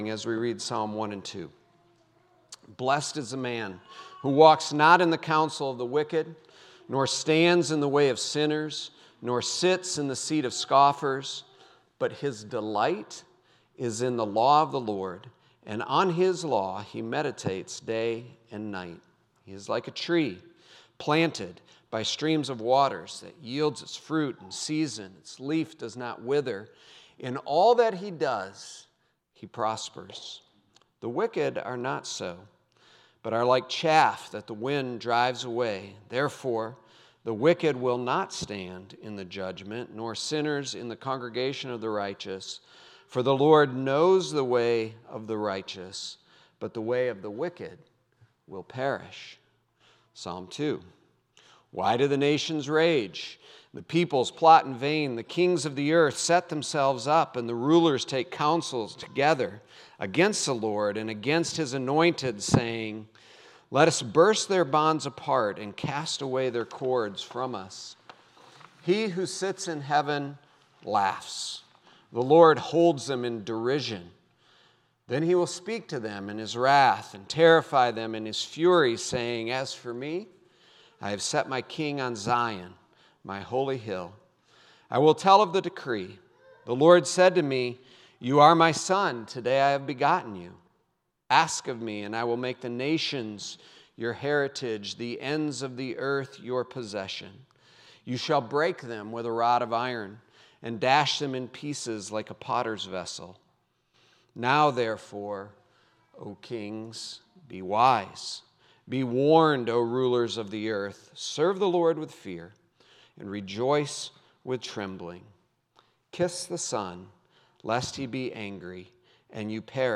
6.8.25 sermon.m4a